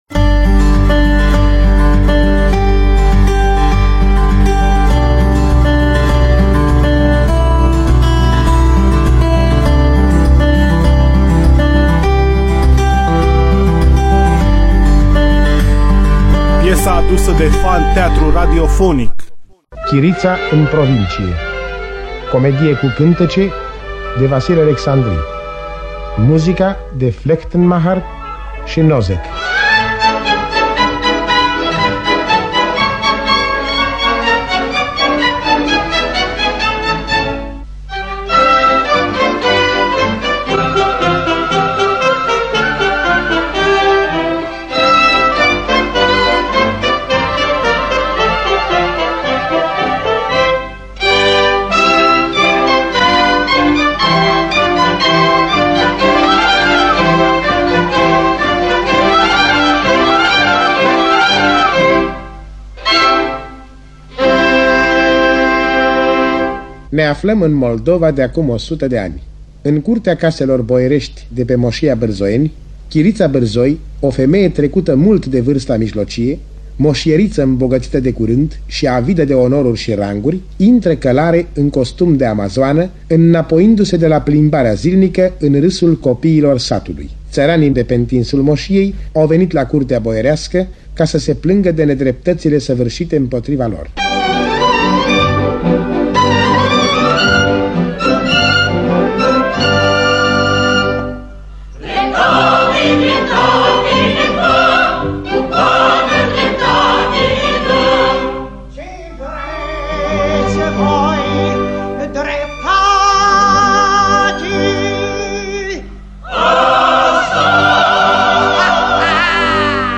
Interpretează un colectiv al Teatrului Naţional „Vasile Alecsandri” din Iaşi.
Interpretează Orchestra Filarmonicii de Stat Moldova